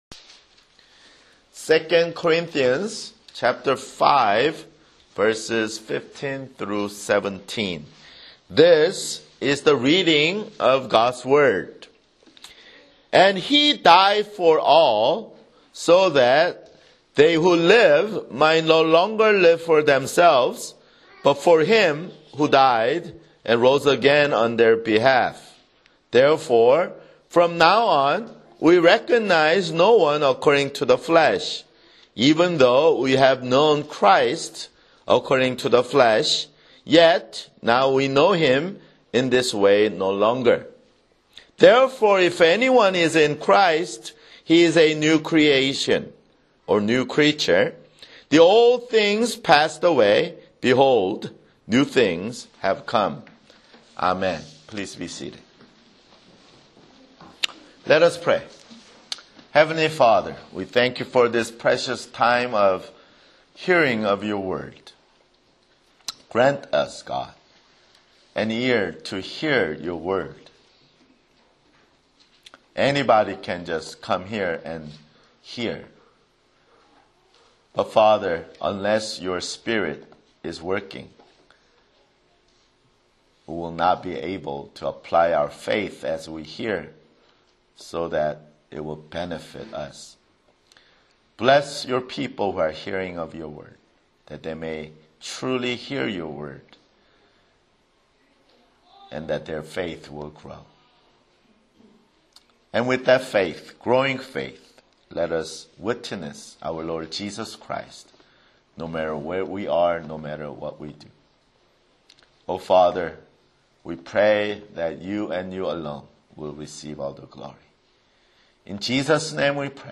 [Sermon] 2 Corinthians 5:15-17 2 Corinthians 5:15-17 (Lord's Supper)